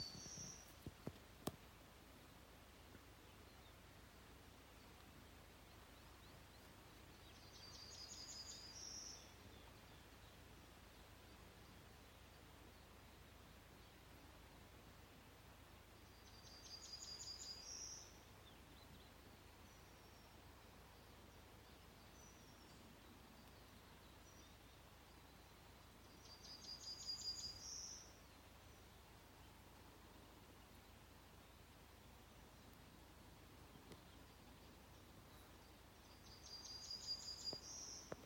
Yellowhammer, Emberiza citrinella
StatusVoice, calls heard